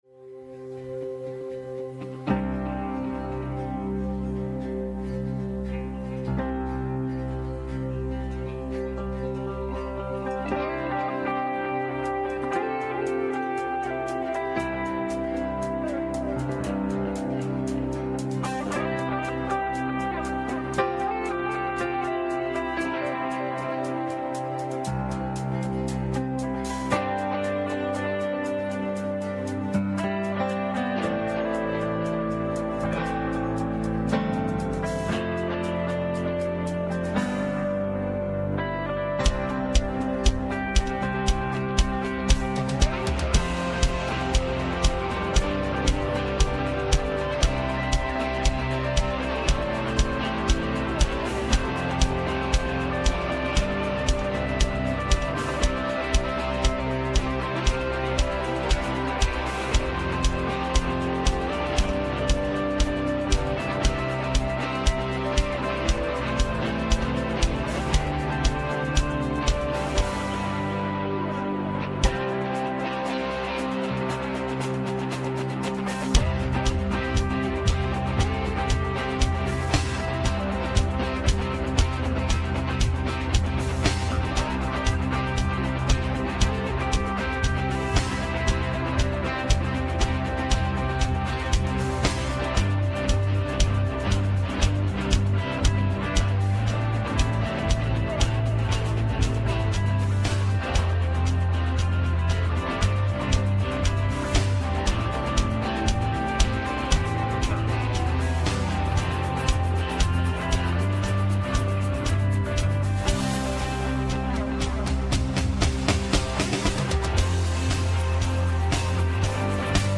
Westgate Chapel Sermons C&MA DNA: Christ Our Coming King Jun 08 2025 | 01:32:59 1x 00:00 / 01:32:59 Subscribe Share Apple Podcasts Overcast RSS Feed Share Link Embed